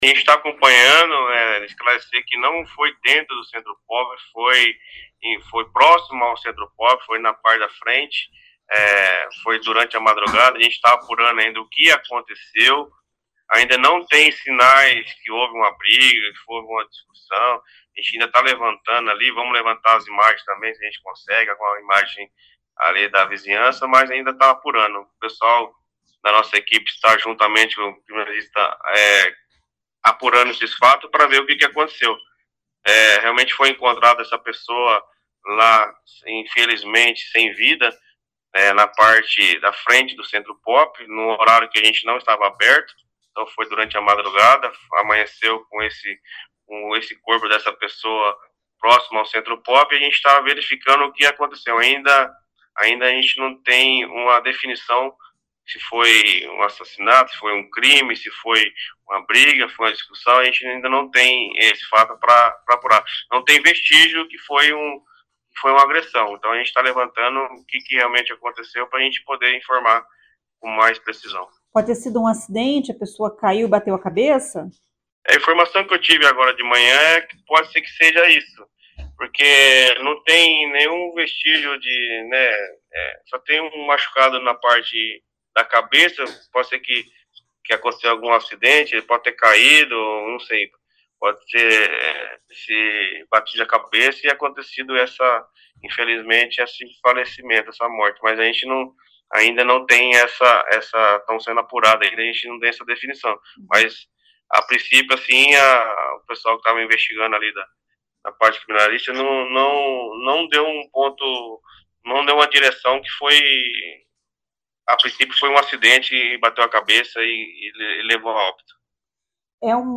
Ouça o que diz o secretário Leandro Bravin.